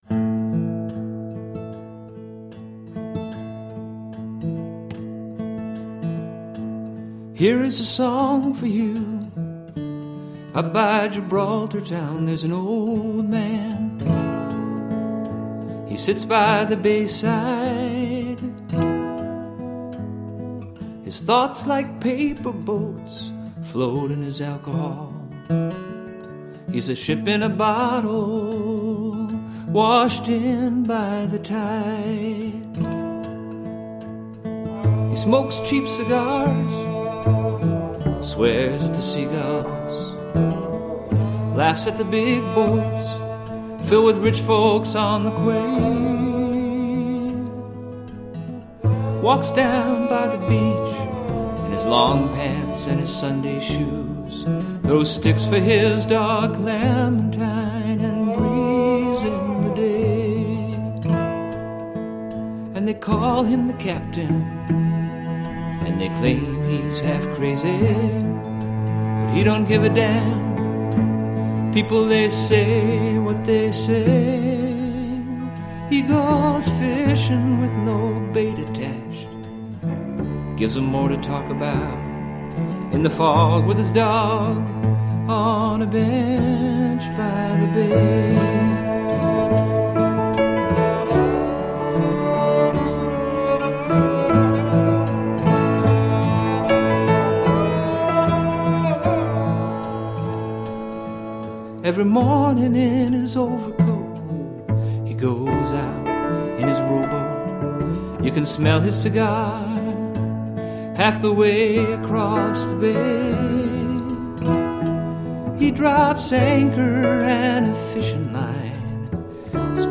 New age meets indian cello.
Tagged as: World, Folk, World Influenced